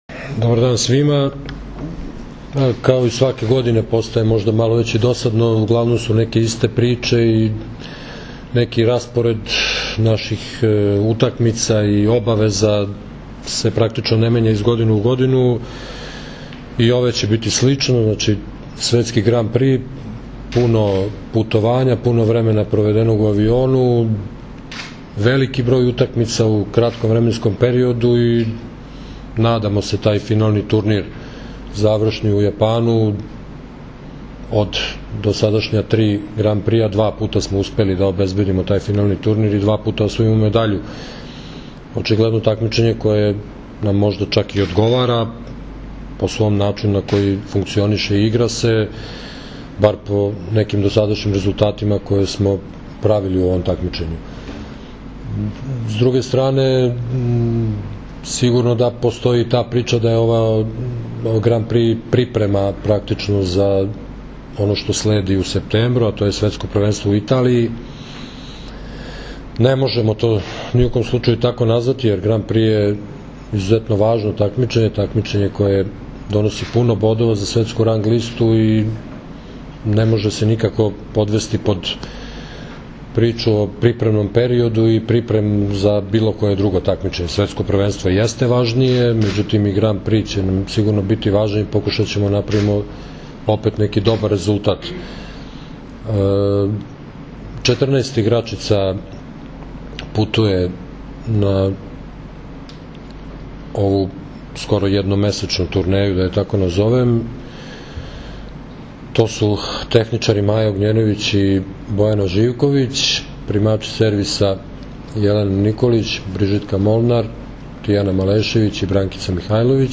Tim povodom, danas je u beogradskom hotelu “M” održana konferencija za novinare, kojoj su prisustvovali Zoran Terzić, Maja Ognjenović, Jelena Nikolić i Milena Rašić.
IZJAVA ZORANA TERZIĆA